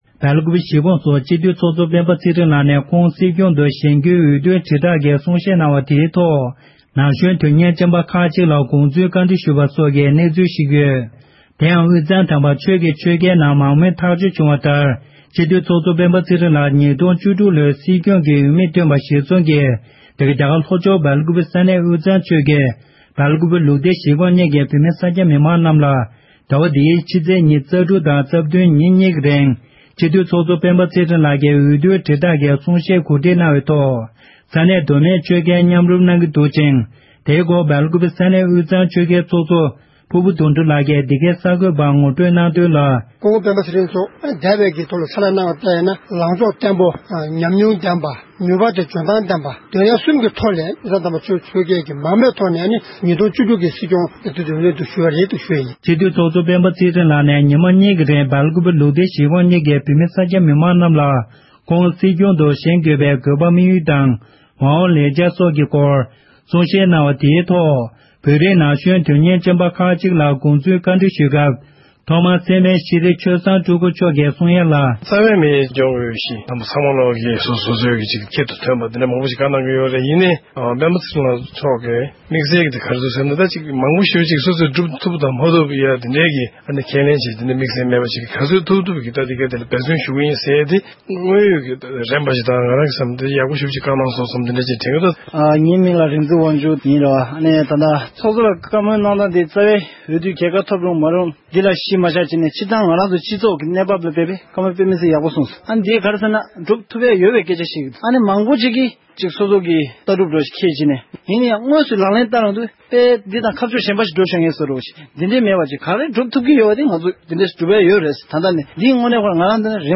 ཚོགས་གཙོའི་གཏམ་བཤད་ཐོག་མི་མང་གི་བསམ་ཚུལ། སྒྲ་ལྡན་གསར་འགྱུར།